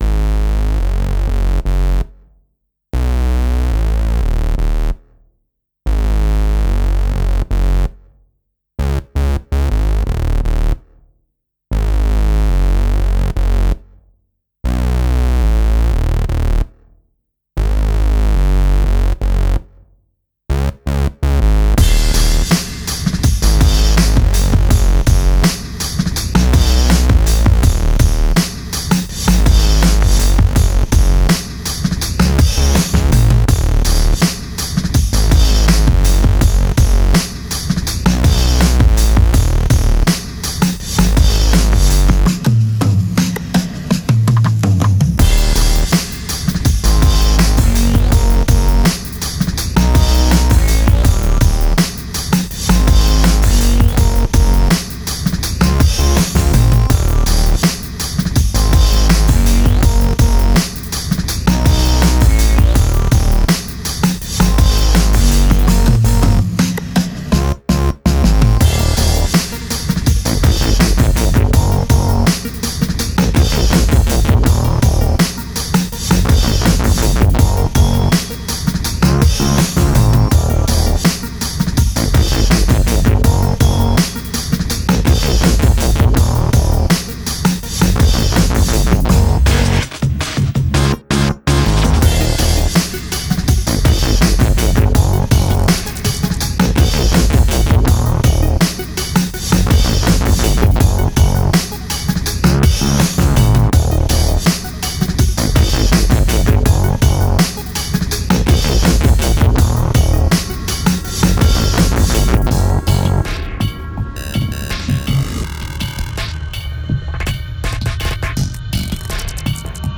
Genre: IDM, Glitch, Downtempo.